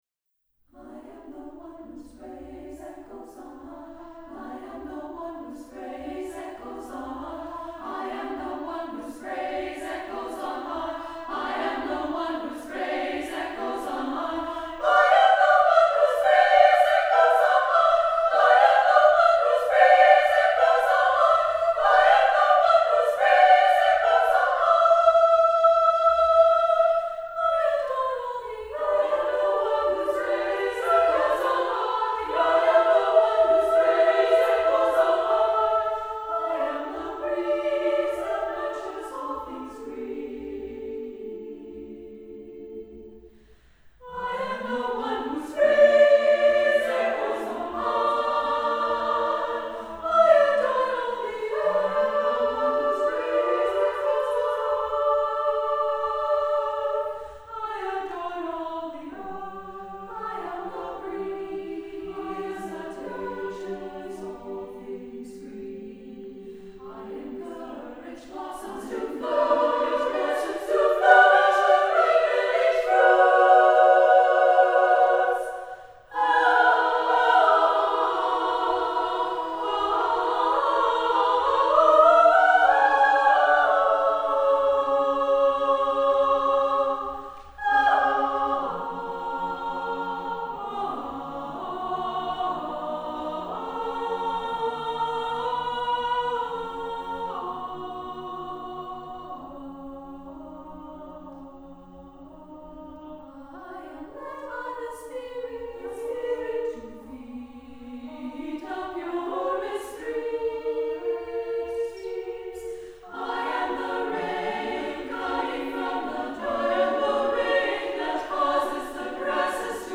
SSAA a cappella
sound as the perpetual, pulsing praise